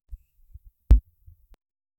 Royalty-free accessories sound effects
sensor-earbuds-touch-efgc6du3.wav